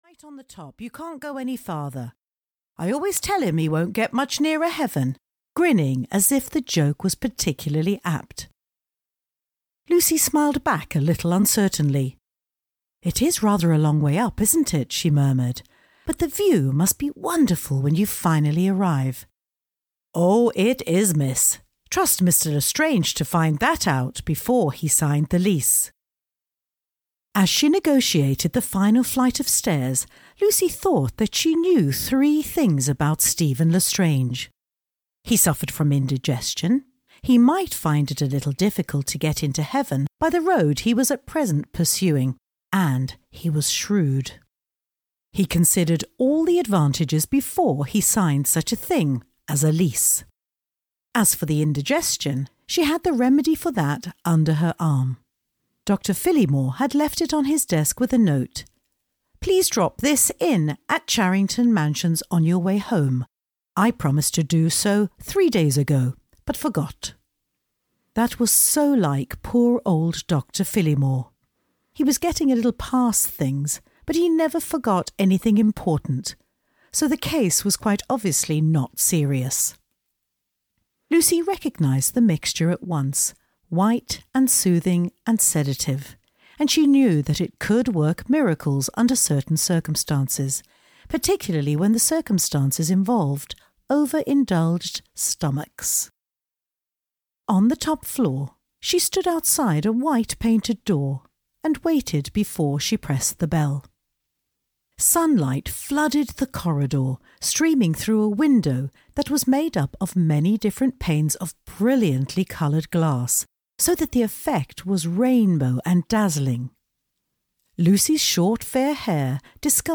Love in the Afternoon (EN) audiokniha
Ukázka z knihy